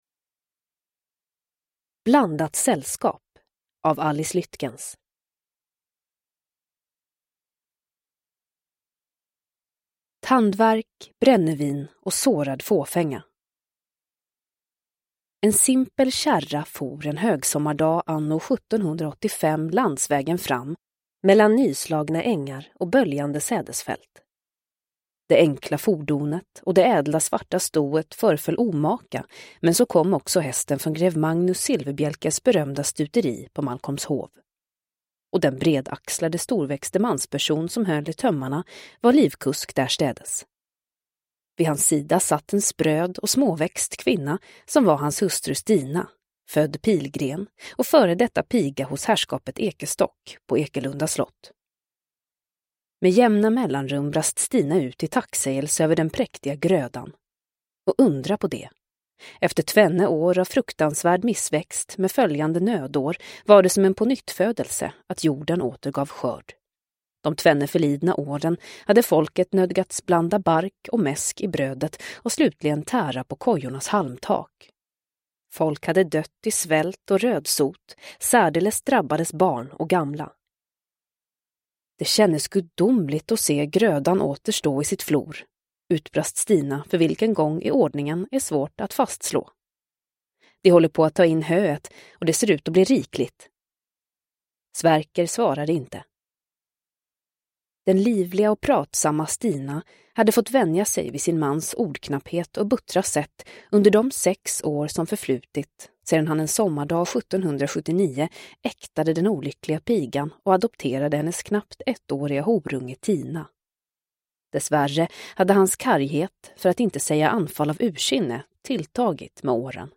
Blandat sällskap – Ljudbok – Laddas ner